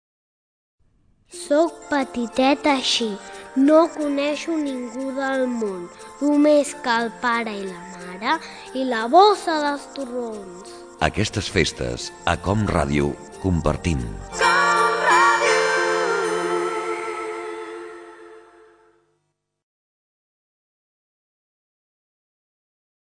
Felicitació de Nadal